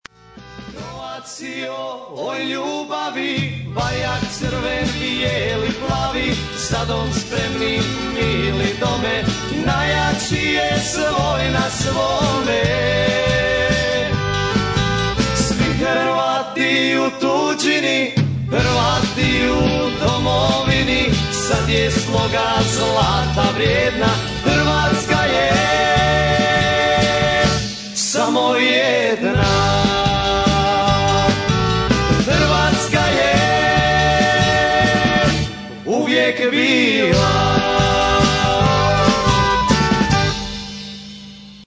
Mixano u "Profile Studios" Vancouver BC
u "Slanina Studios" Vancouver BC...